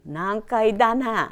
Aizu Dialect Database
Type: Statement
Final intonation: Falling
Location: Showamura/昭和村
Sex: Female